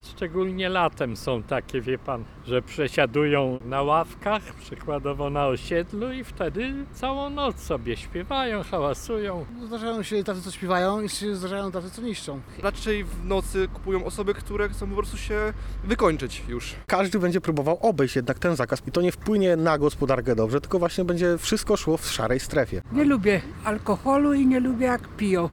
Inicjatywa budzi emocje wśród mieszkańców. Posłuchaj, co mówią na ten temat: